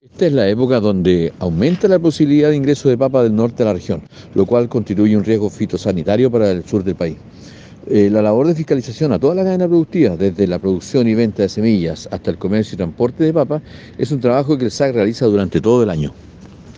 Cuna-Seremi-Patricio-Barria.mp3